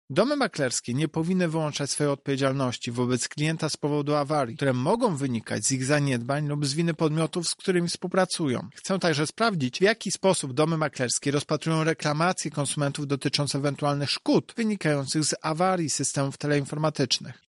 O szczegółach prowadzonych kontroli w 10 instytucjach finansowych mówi Prezes Urzędu Ochrony Konkurencji i Konsumentów Tomasz Chróstny: